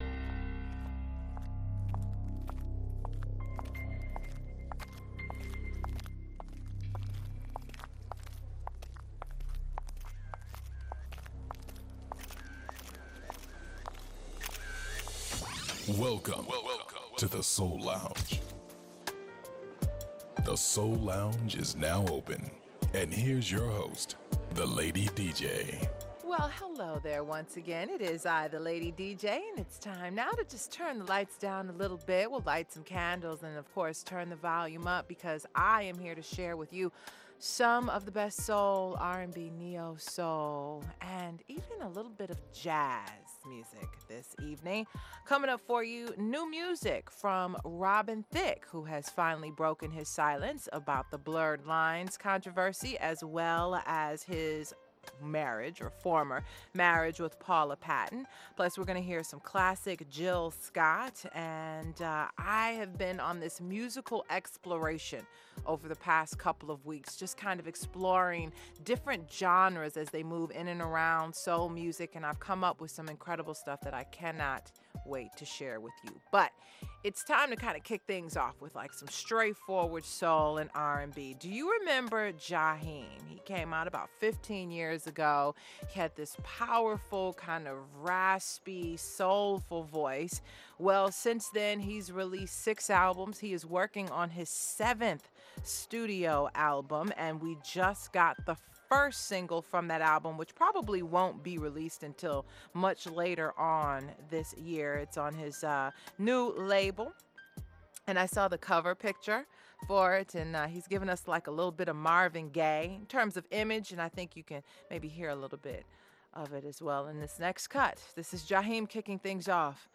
You’ll also hear Classic Soul from legendary musicians who have inspired a the new generation of groundbreaking artists.